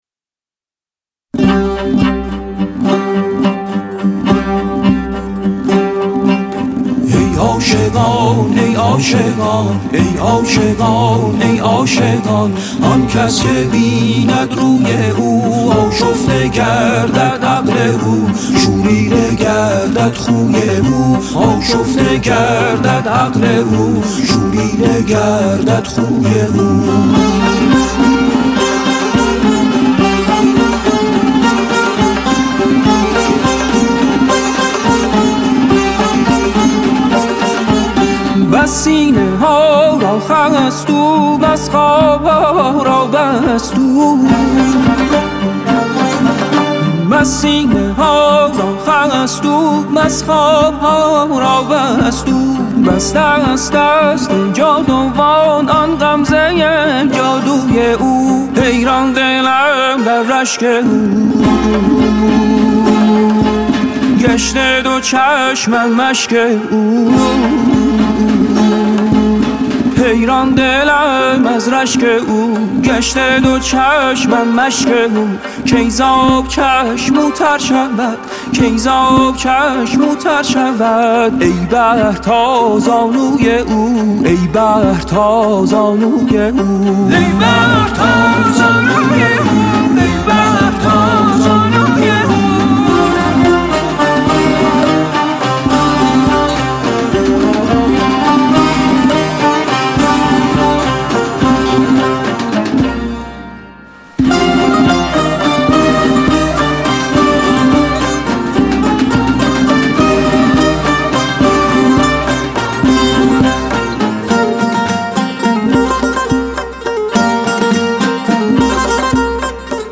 عود
سنتور
تنبک
دف
کمانچه
سه تار بم